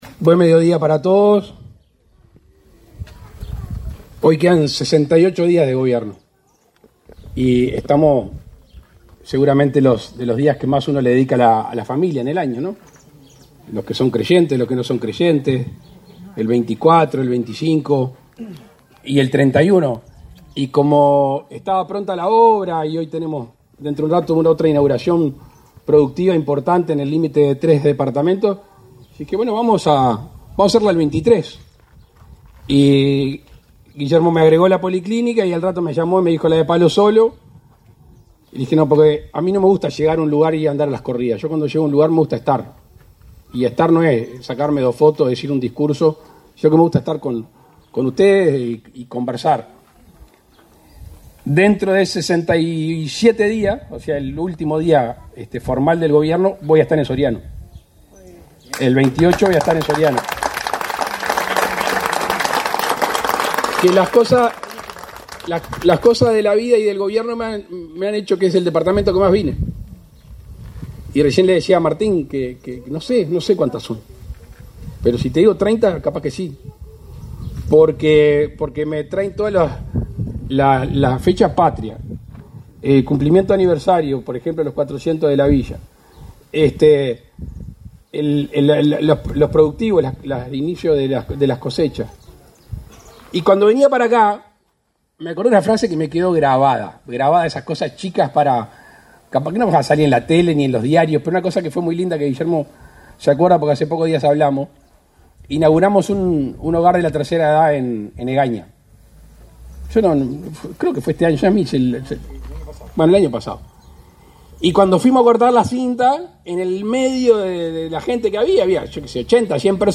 Palabras del presidente de la República, Luis Lacalle Pou
Palabras del presidente de la República, Luis Lacalle Pou 23/12/2024 Compartir Facebook X Copiar enlace WhatsApp LinkedIn El presidente de la República, Luis Lacalle Pou, participó, este 23 de diciembre, en la inauguración de la reforma de la policlínica de la localidad de Risso, en el departamento de Soriano.